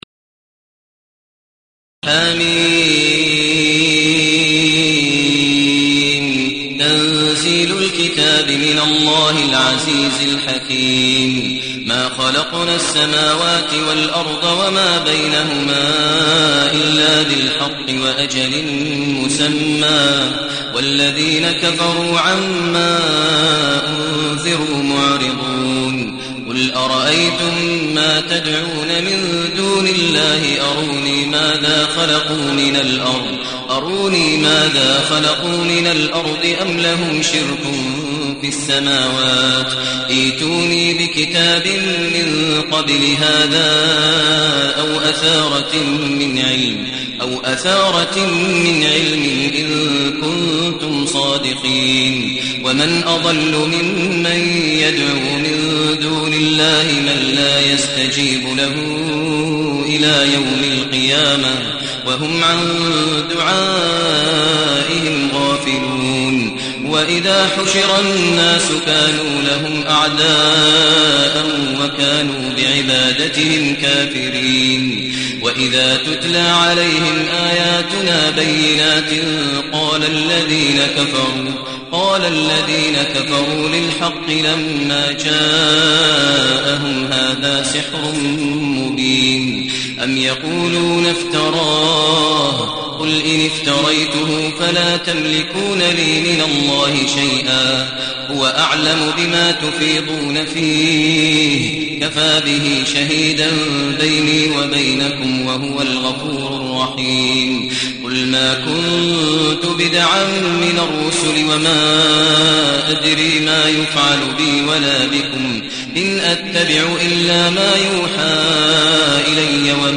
المكان: المسجد النبوي الشيخ: فضيلة الشيخ ماهر المعيقلي فضيلة الشيخ ماهر المعيقلي الأحقاف